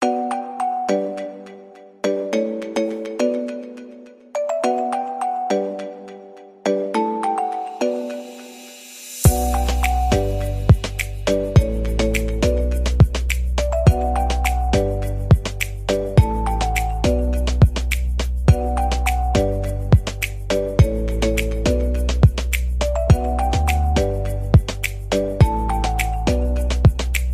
Beutiful melody for ringtone